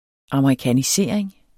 Udtale [ ɑmɑikaniˈseˀɐ̯eŋ ]